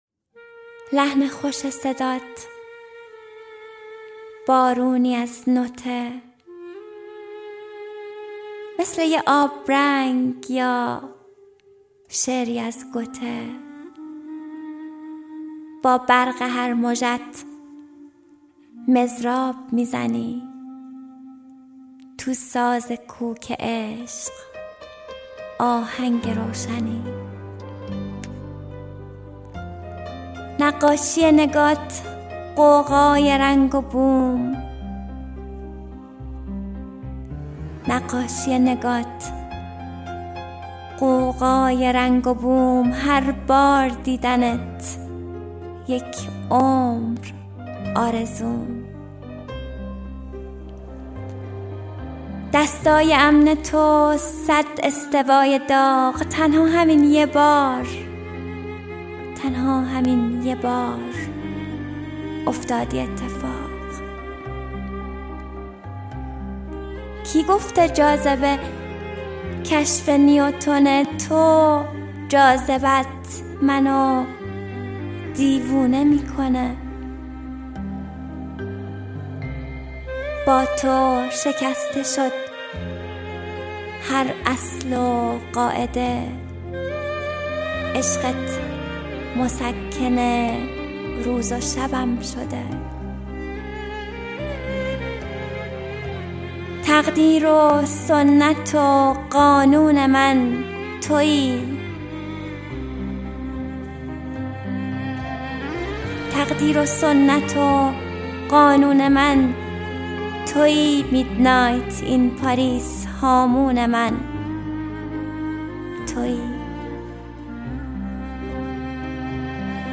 دانلود دکلمه آبرنگ با صدای مریم حیدر زاده با متن دکلمه